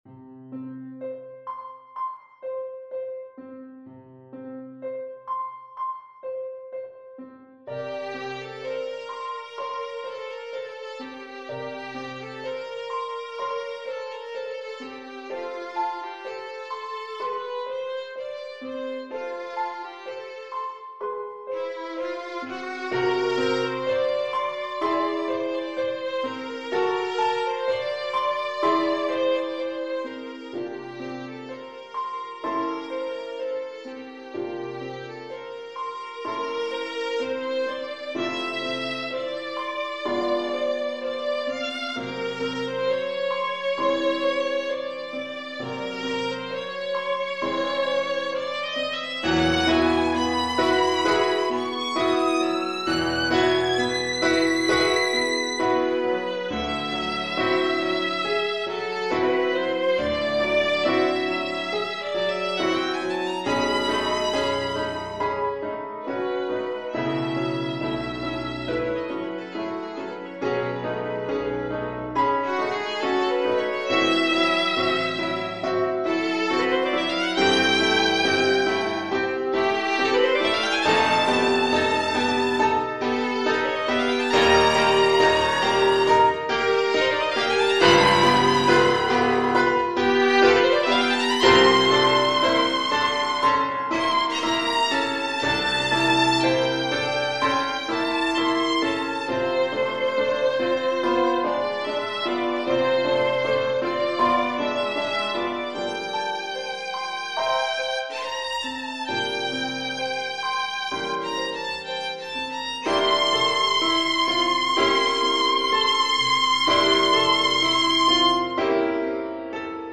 Violin
F major (Sounding Pitch) (View more F major Music for Violin )
4/4 (View more 4/4 Music)
Assez lent =63
Classical (View more Classical Violin Music)
boulanger_nocturne_VLN.mp3